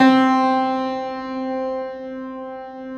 53c-pno10-C2.wav